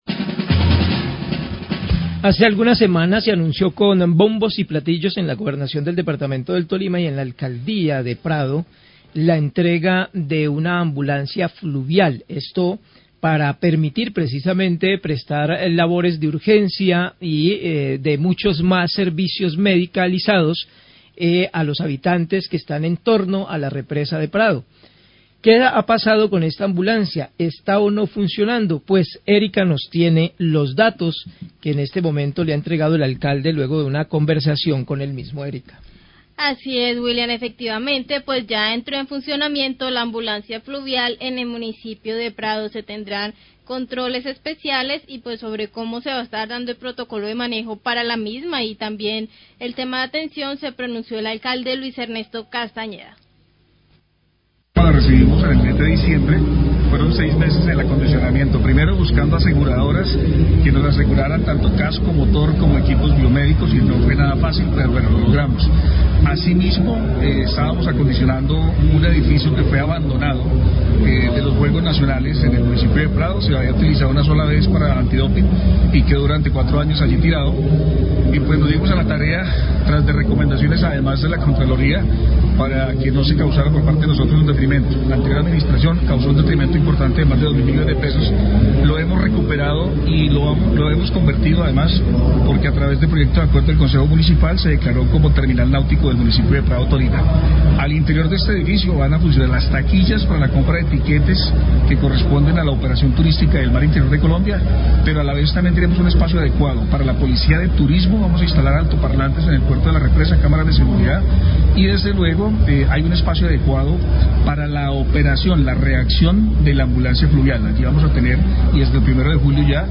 Alcalde El Prado habla de inicio operaciones de ambulancia fluvial en la represa
El Alcalde del Prado, Luis Ernesto Castañeda, habla del iniico de operaciones de la ambulancia fluvial en la represa. También se refiere una edificación de los Juegos Nacionales que había sido abandona y que restauró para ser usada como terminal nautica.